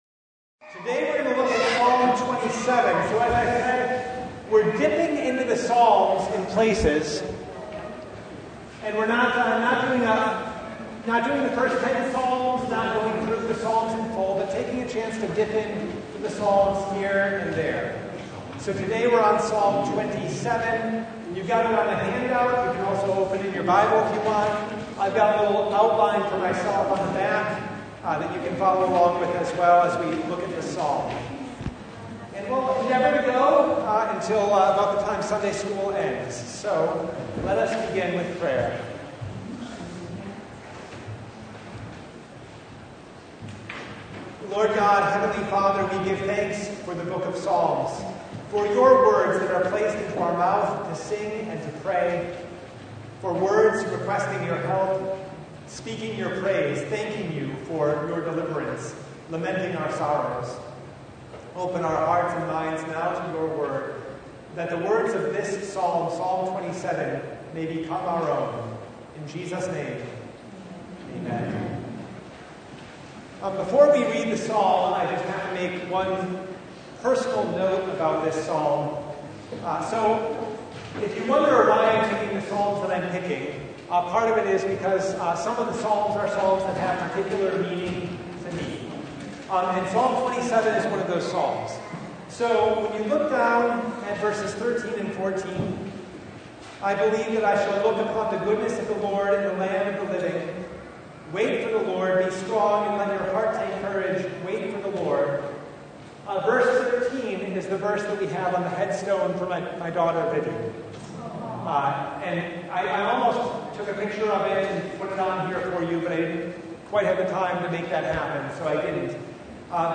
Psalm 27 Service Type: Bible Study The Lord is my light and my salvation